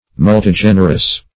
Search Result for " multigenerous" : The Collaborative International Dictionary of English v.0.48: Multigenerous \Mul`ti*gen"er*ous\, a. [L. multigenerus; multus + genus, generis, kind.]
multigenerous.mp3